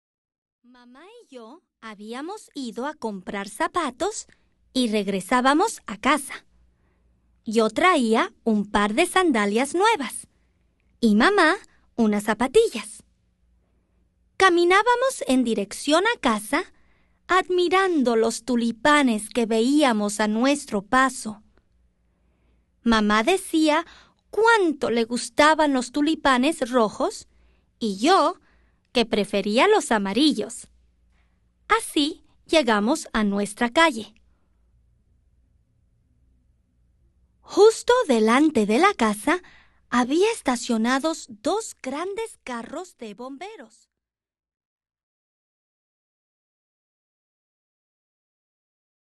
Spanish Readalongs